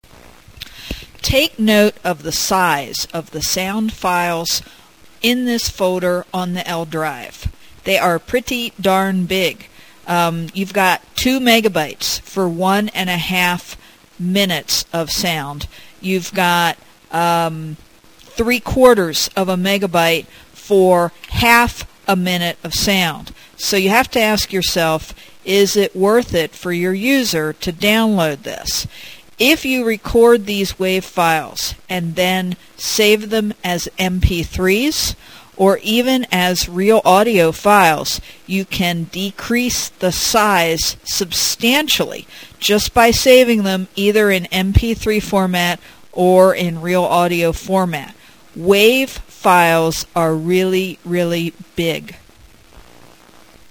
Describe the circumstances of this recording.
For voice quality equivalent to what you hear on these pages, you can record at 11.025 KHz 8-bit mono.